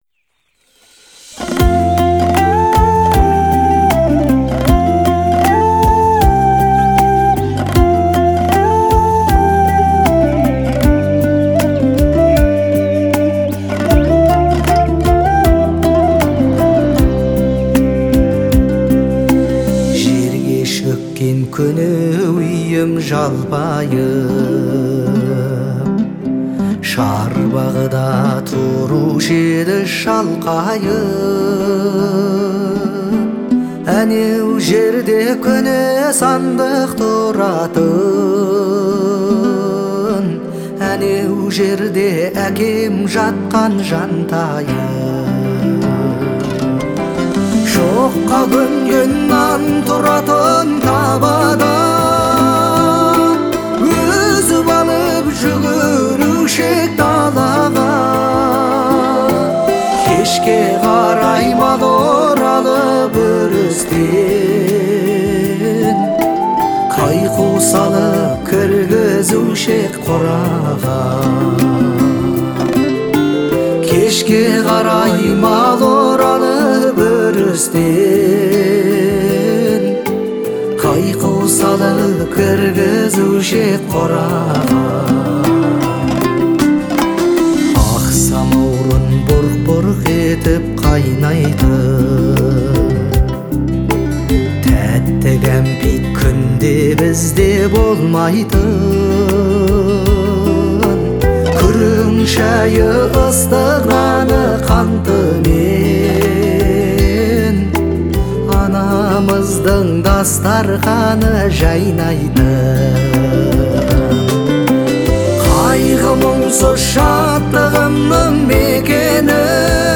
это трогательное произведение в жанре народной музыки